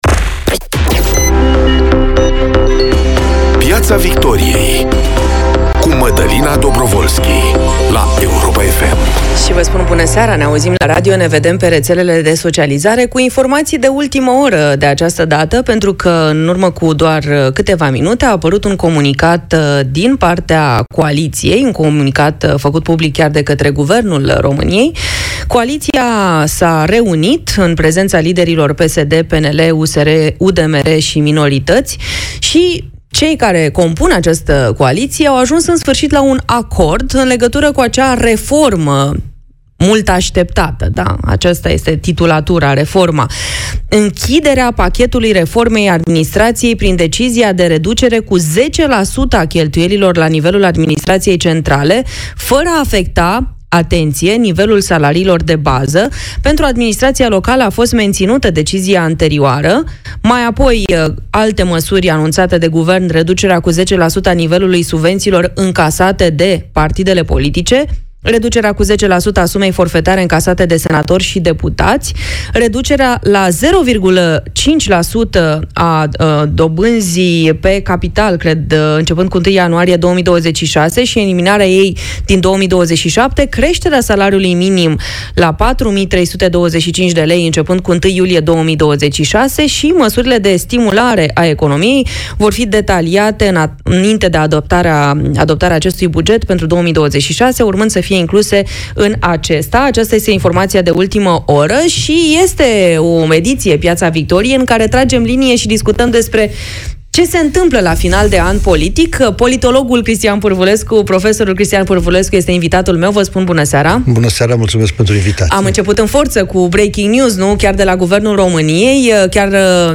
vine în această seară în studioul Europa FM.